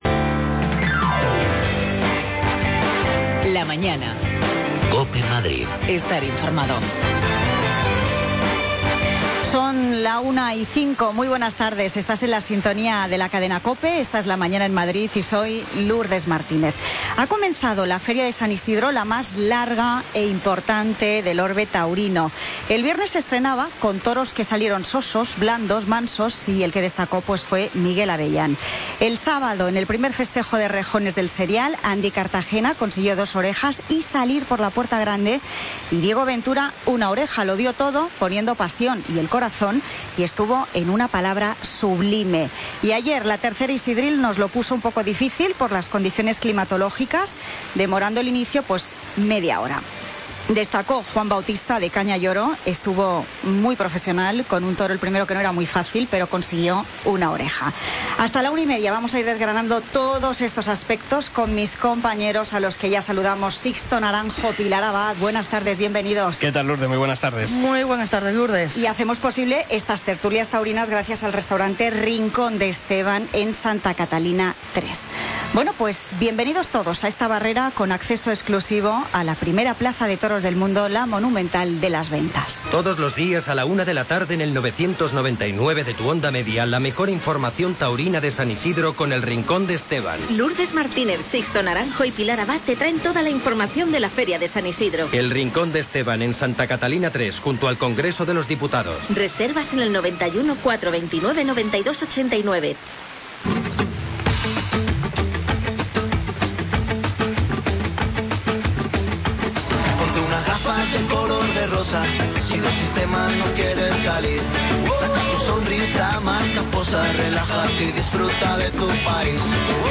Tertulia Taurina Feria San Isidro COPE Madrid, lunes 9 de mayo de 2016